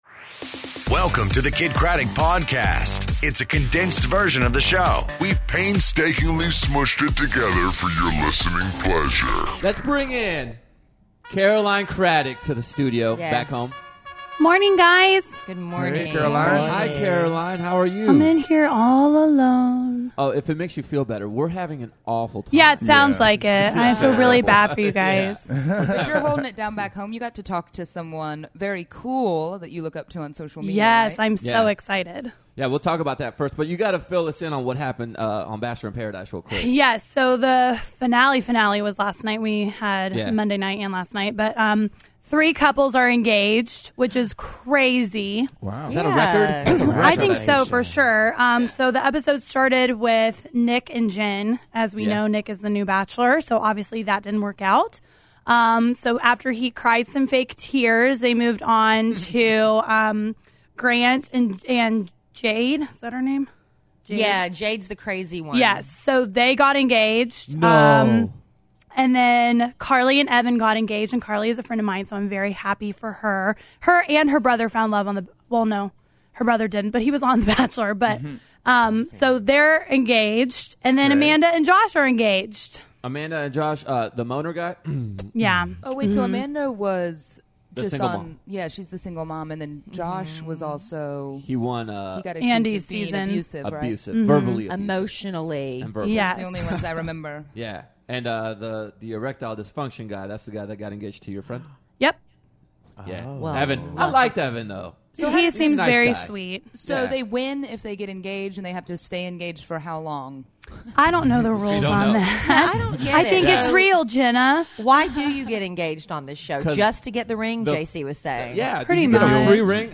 Day 2 Of The KiddNation Family Vacation Live From Punta Cana